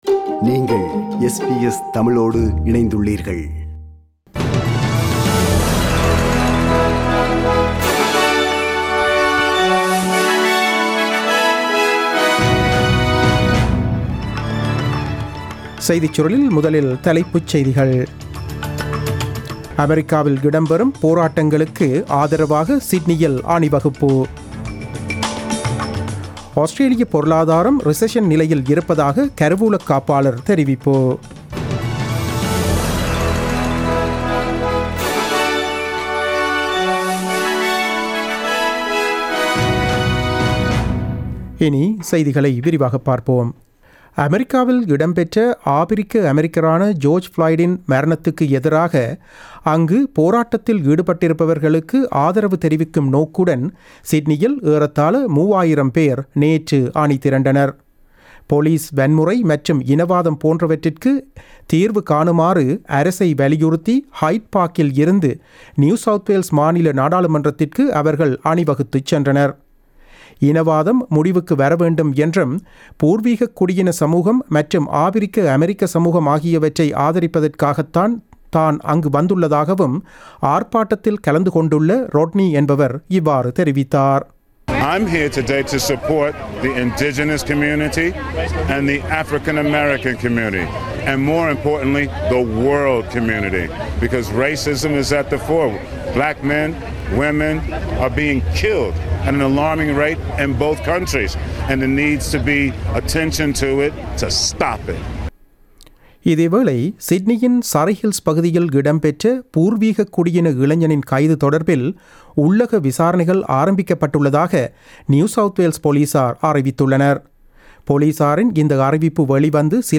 The news bulletin broadcasted on 03 June 2020 at 8pm.